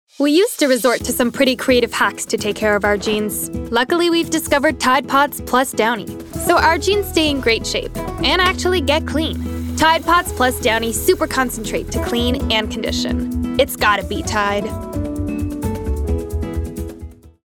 Commercial (1) - EN